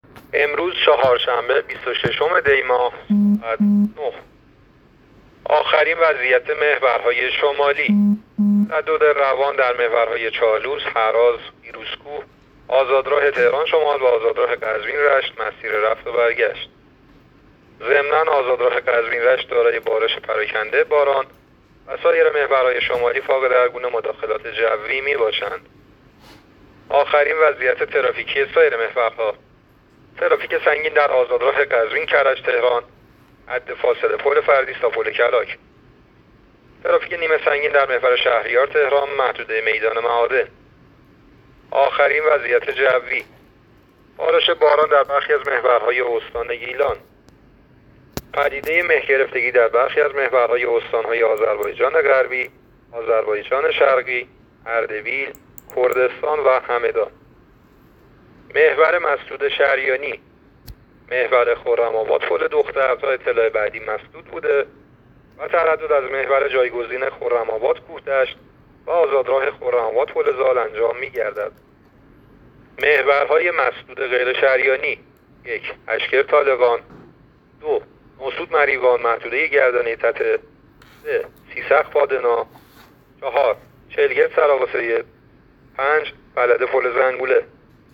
گزارش رادیو اینترنتی از آخرین وضعیت ترافیکی جاده‌ها تا ساعت ۹ بیست و ششم دی؛